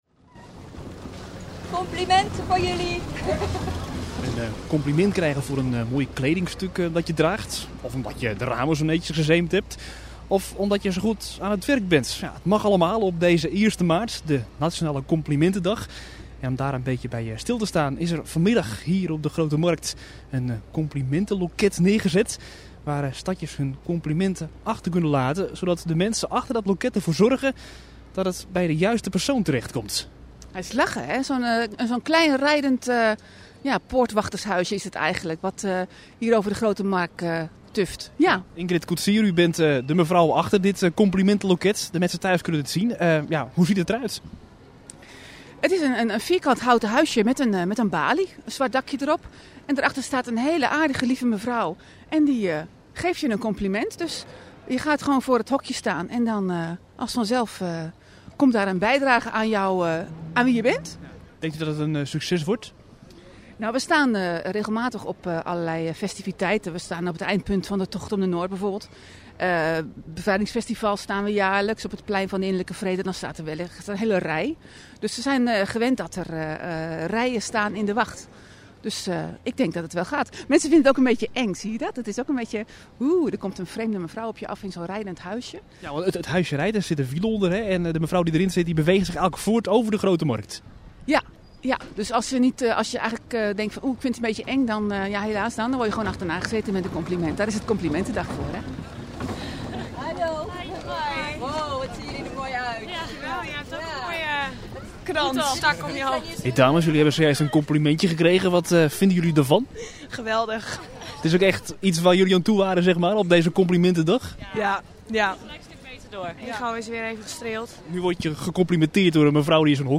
Op de Grote Markt werd in de middag een Complimentenloket neergezet.